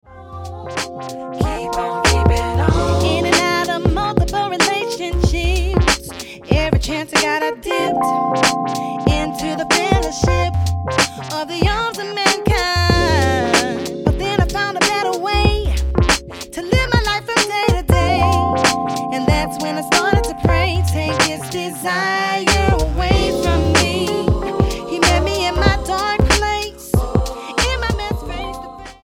gospel singer
Style: R&B